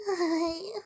birdo_panting_cold.ogg